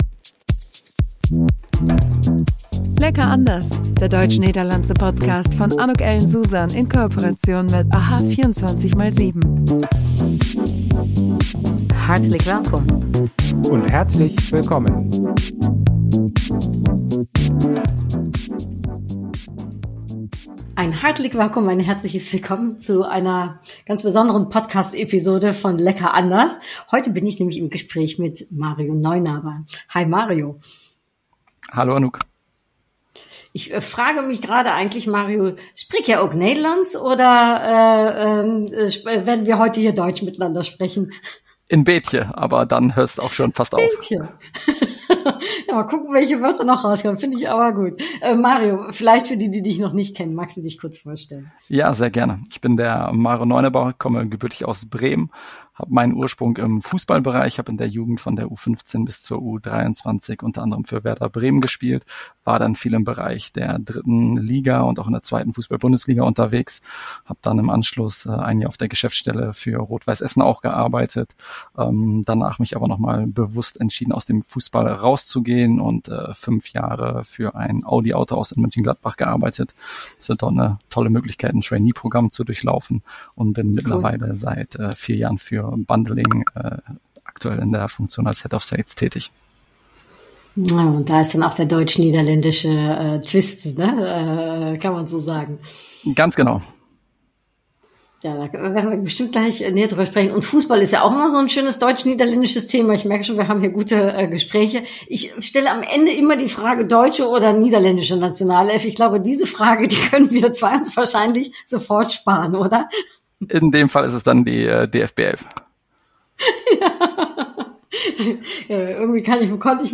Alle 2 Wochen gibt es spannende Interviews mit einer deutschen Person of met een Nederlandse persoon. Personen aus den Bereichen Kultur, Wirtschaft, Politik, Sport, Medien, Grenzgänger und vieles mehr….